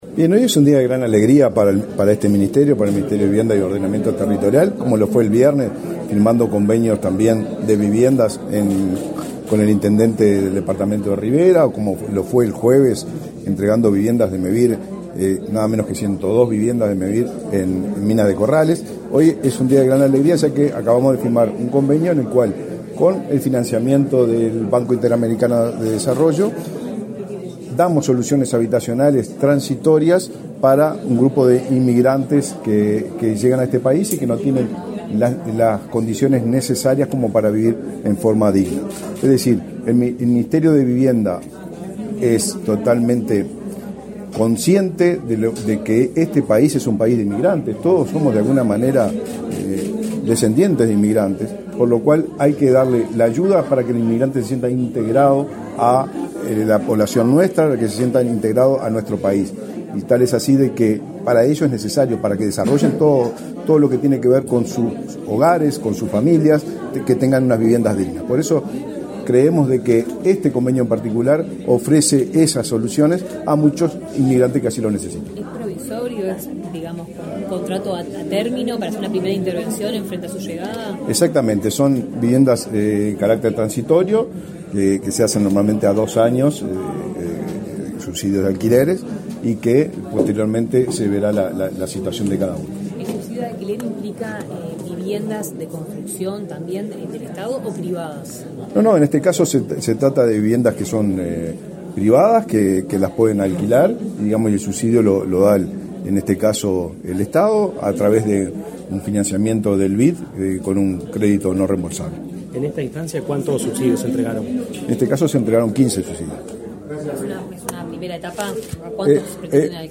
Declaraciones a la prensa del ministro de Vivienda y Ordenamiento Territorial, Raúl Lozano
Declaraciones a la prensa del ministro de Vivienda y Ordenamiento Territorial, Raúl Lozano 23/10/2023 Compartir Facebook X Copiar enlace WhatsApp LinkedIn El Gobierno otorgará subsidios de alquiler de viviendas a familias migrantes, tras el convenio firmado, este 23 de octubre, entre los ministerios de Vivienda y Desarrollo Social. Tras la rúbrica, el ministro Raúl Lozano realizó declaraciones a la prensa.